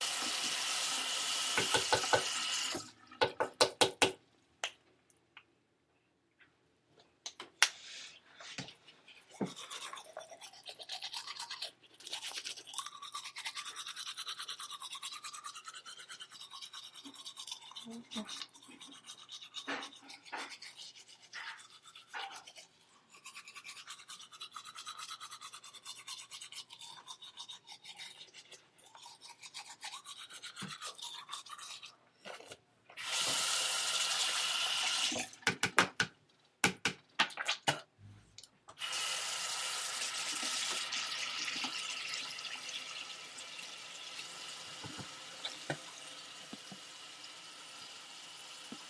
Sounds heard: running water, tapping, brushing teeth
field-recording-21.mp3